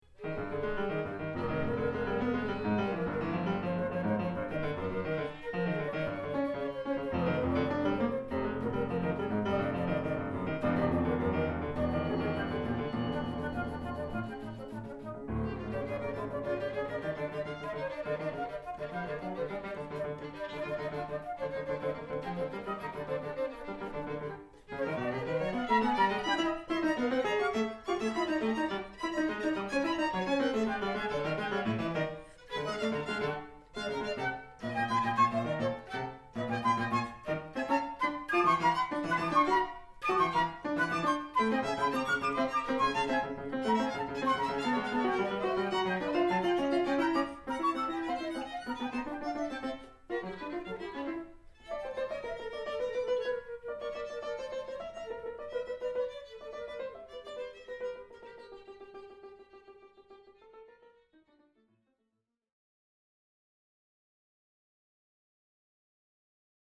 for flute/violin/piano trio (2010) [世界初演 / world première]
場所：中目黒GTプラザホール